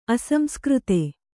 ♪ asamskřte